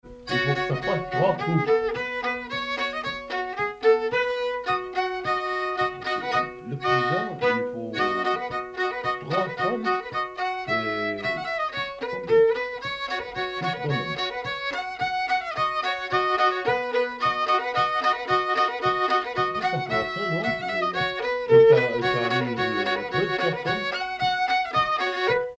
Localisation Saint-Nicolas-la-Chapelle
Pièce musicale inédite